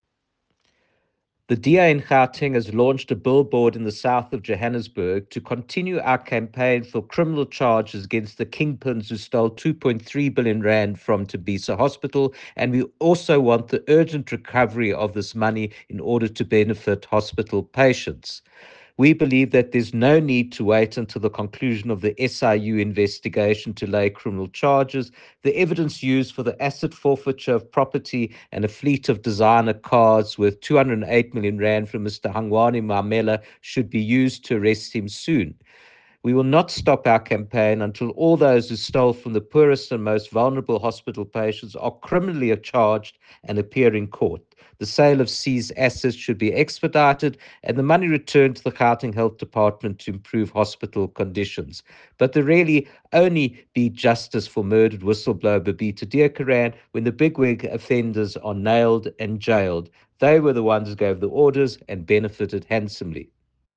soundbite by Dr Jack Bloom.